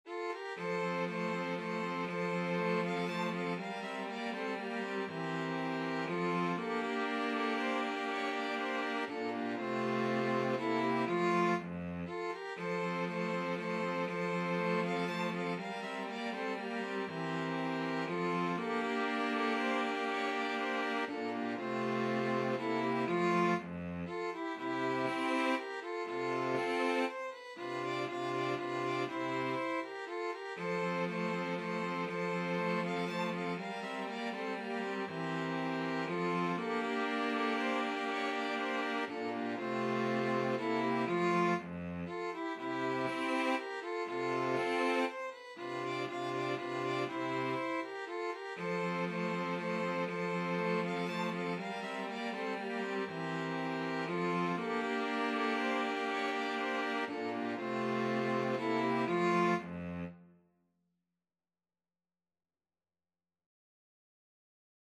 Classical Haydn, Franz Josef Minuet in F No 11 (Little Serenade ) Hob IX:8 String Quartet version
Violin 1Violin 2ViolaCello
3/4 (View more 3/4 Music)
F major (Sounding Pitch) (View more F major Music for String Quartet )
Classical (View more Classical String Quartet Music)